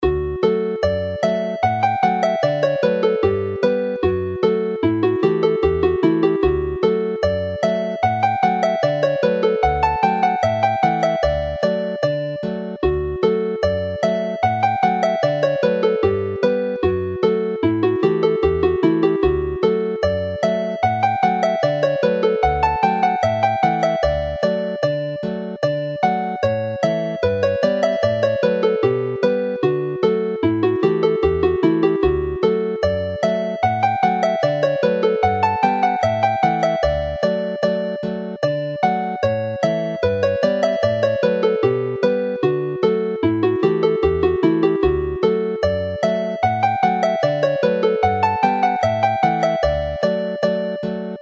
Welsh folk tunes to play - Morys Morgannwg, Gorymdaith Morys, Nos Fercher